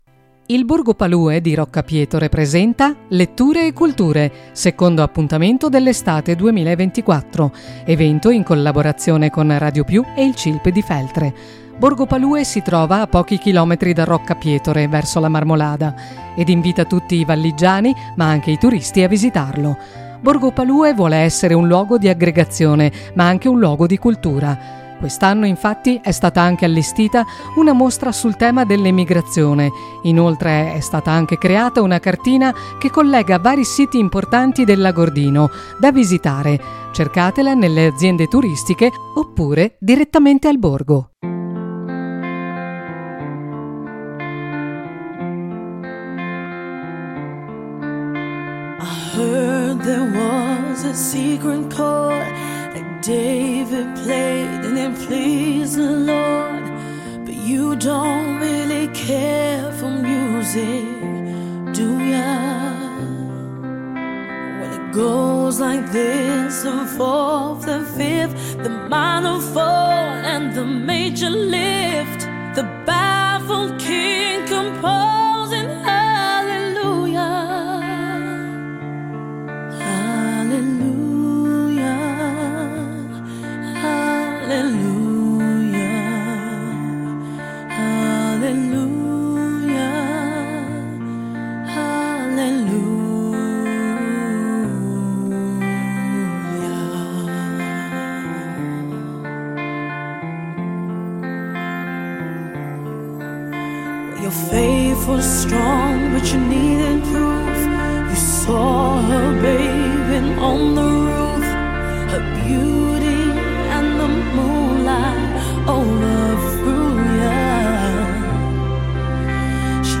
Appuntamento con le letture ai visitatori con la presenza del cilip di Feltre e Radio Più.
L’evento dopo le letture ha acceso un bellissimo dibattito tra i presenti, creando un’atmosfera di scambio non solo culturale ma anche e soprattutto conviviale.